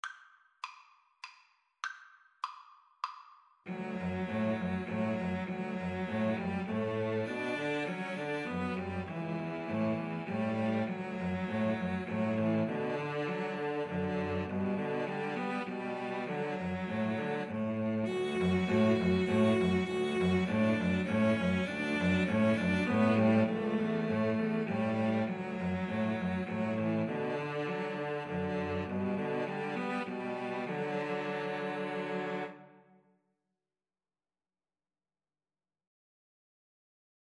Play (or use space bar on your keyboard) Pause Music Playalong - Player 1 Accompaniment Playalong - Player 3 Accompaniment reset tempo print settings full screen
Cello 1Cello 2Cello 3
"Arirang" is a Korean folk song, often considered as the unofficial national anthem of Korea.
3/4 (View more 3/4 Music)
D major (Sounding Pitch) (View more D major Music for Cello Trio )
Moderato